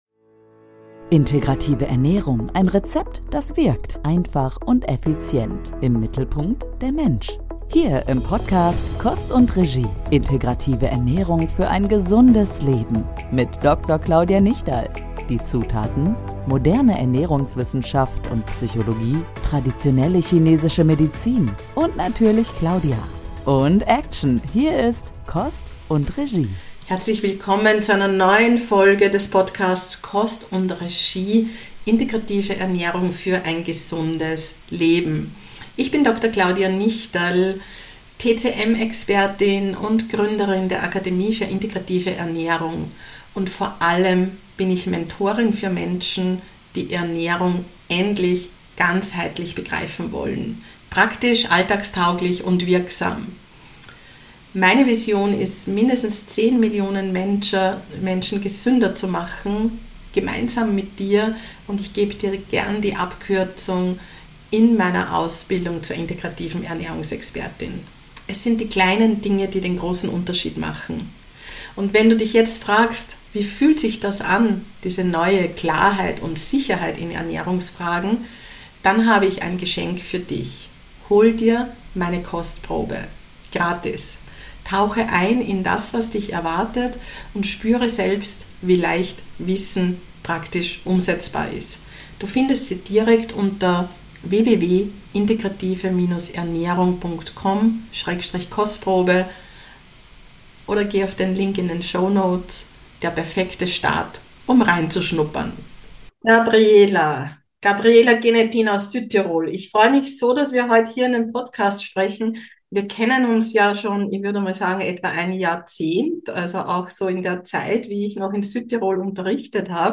1 #076 Von Fasten zum Detox - Wie kleine Schritte dein Leben verändern: Im Interview